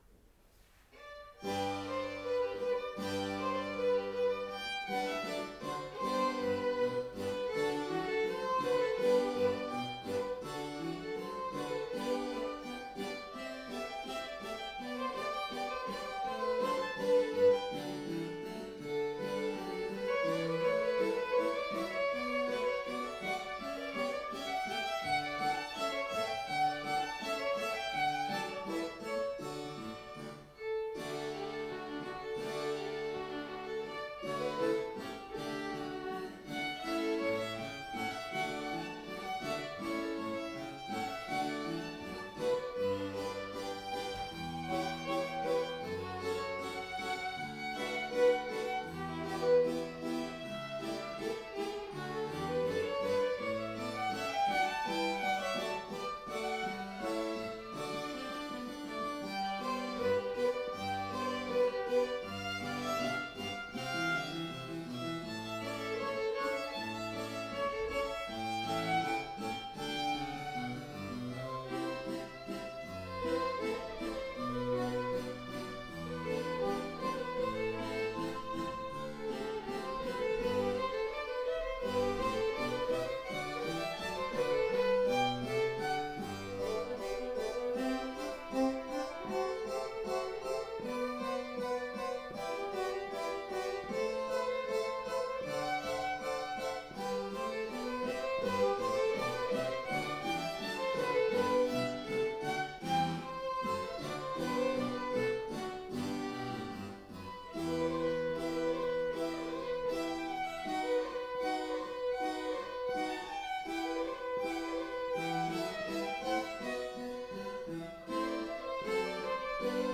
Allegro per violino e clavicembalo di Joseph Hector Fiocco
violino
clavicembalo
Dal Concerto del 16 ottobre 2016